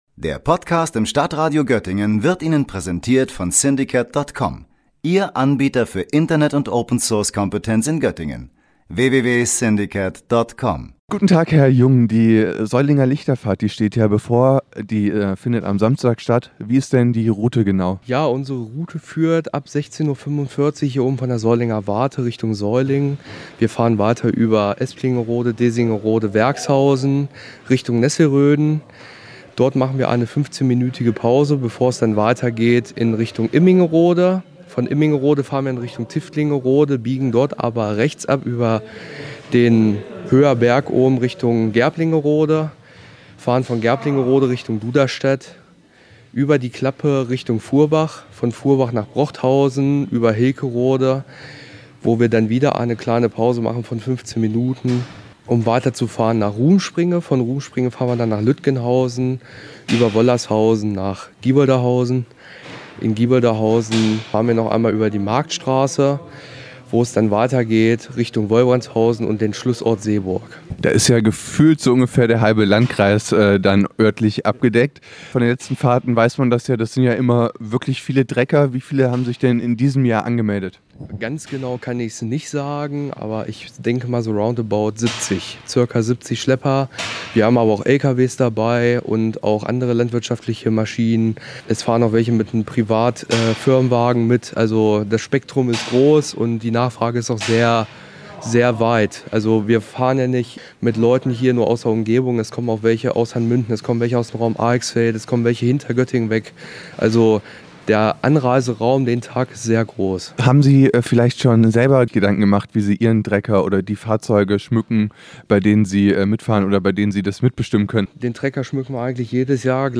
Beiträge > Lichterfahrt Seulingen: Ablauf, Spendenzweck und Aktionen - StadtRadio Göttingen